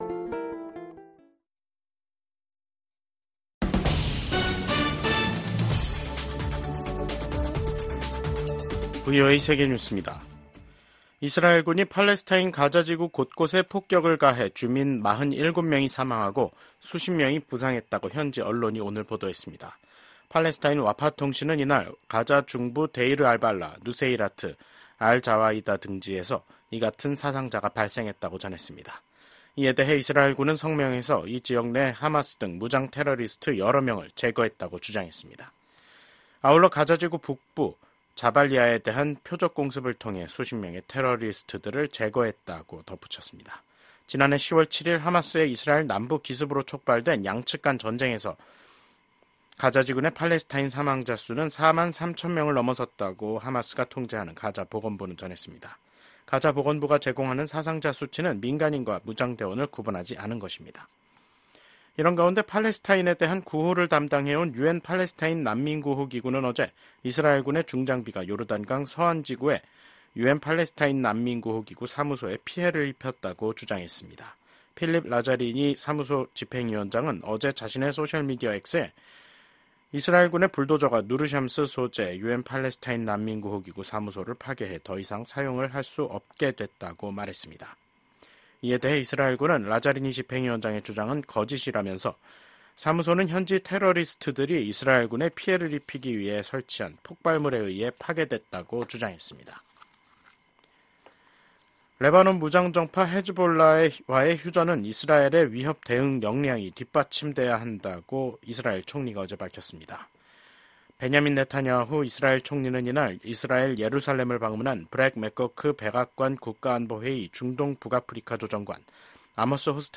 VOA 한국어 간판 뉴스 프로그램 '뉴스 투데이', 2024년 11월 1일 3부 방송입니다. 북한은 신형 대륙간탄도미사일(ICBM) ‘화성-19형’을 시험발사했고 이 ICBM이 ‘최종완결판’이라고 주장했습니다. 러시아에 파병된 북한군이 곧 전투에 투입될 것으로 예상된다고 미국 국무·국방장관이 밝혔습니다. 북한군 8천 명이 우크라이나와 가까운 러시아 쿠르스크 지역에 있다는 정보를 입수했다고 미국 유엔 차석대사가 밝혔습니다.